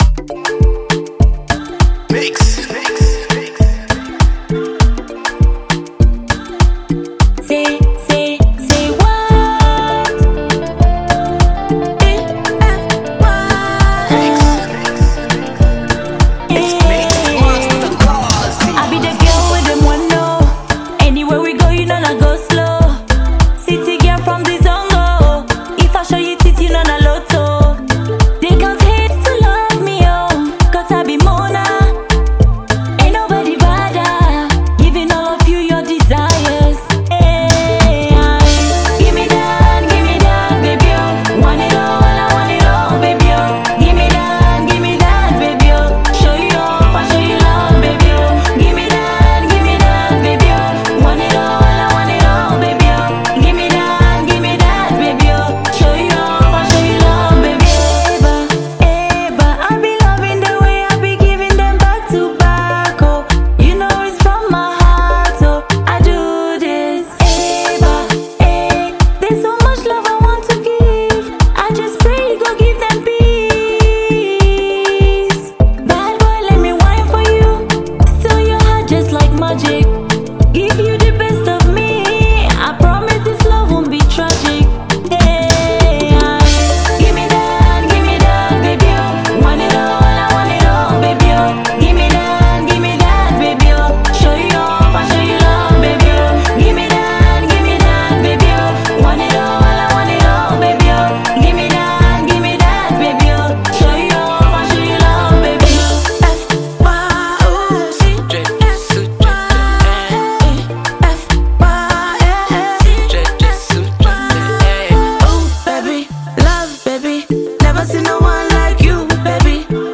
Ghanaian socialite and renowned songstress
catchy new single